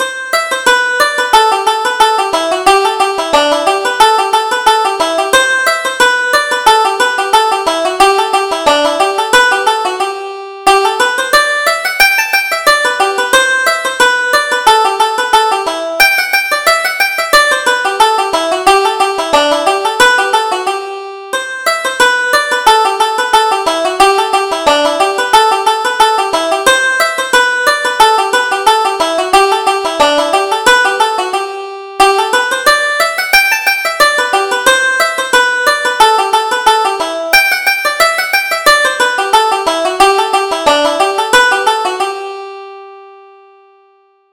Reel: The Green Fields of America